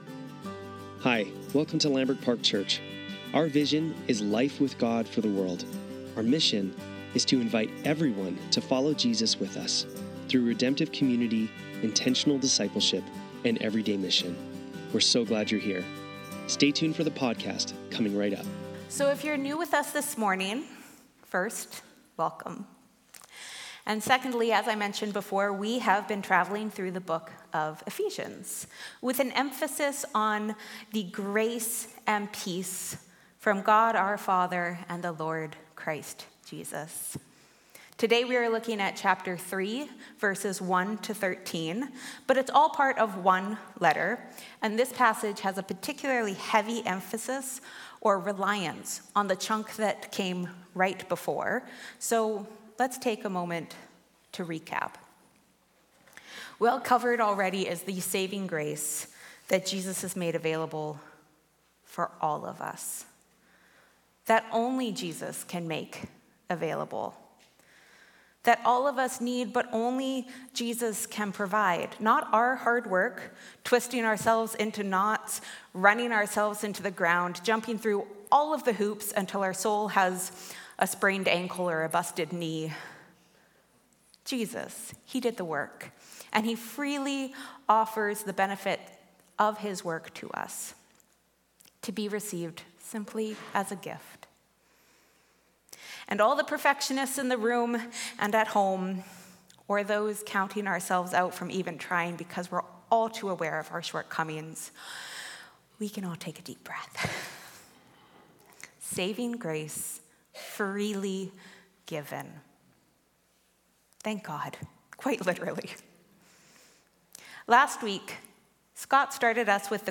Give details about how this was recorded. Sunday Service - November 24, 2024